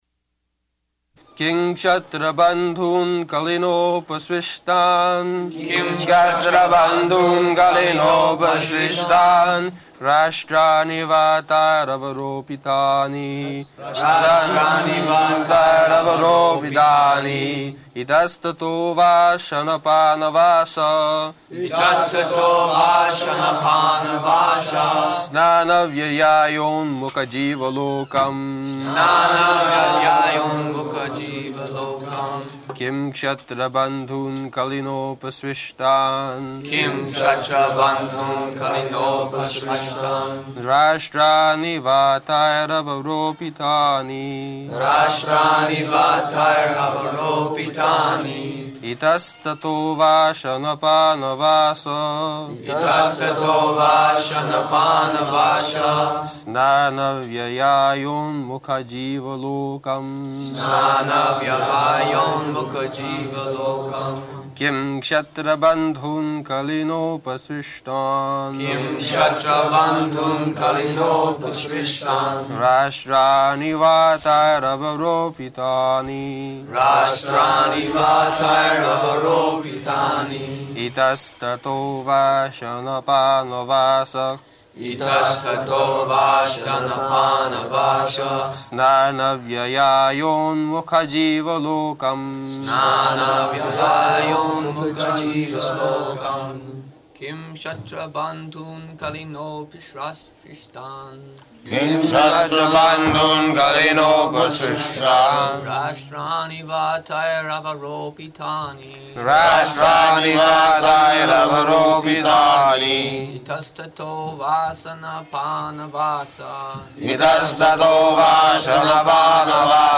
Location: Honolulu
[devotees repeat]